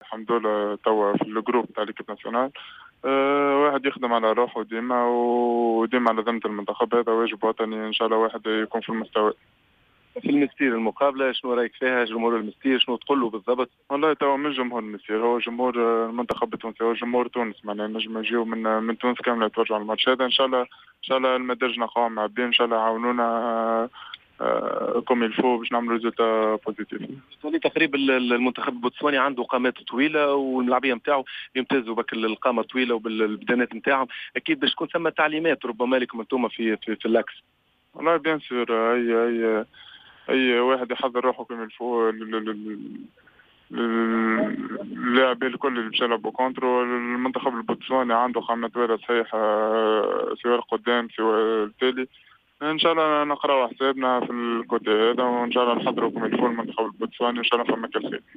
كان حاضرا في الحصّة التدريبيّة المسائيّة وقام بالحوارات التالية: